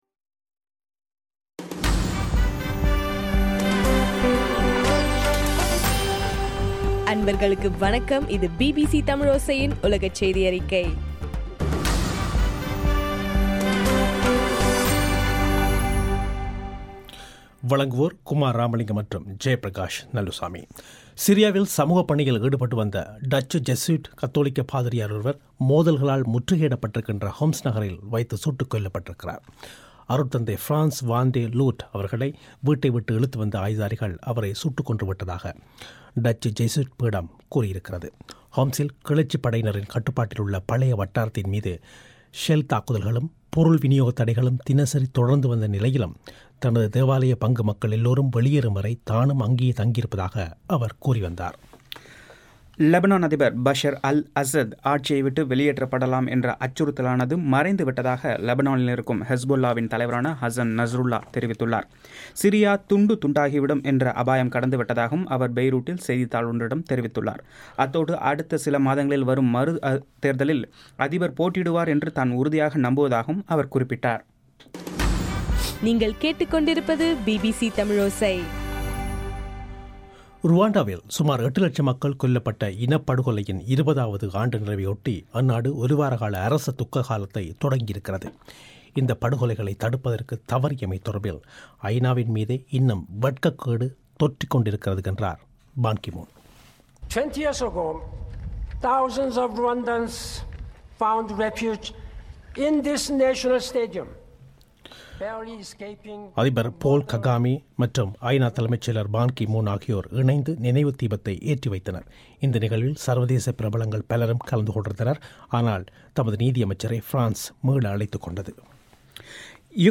இன்றைய (ஏப்ரல் 7) பிபிசி தமிழோசை உலகச் செய்தி அறிக்கை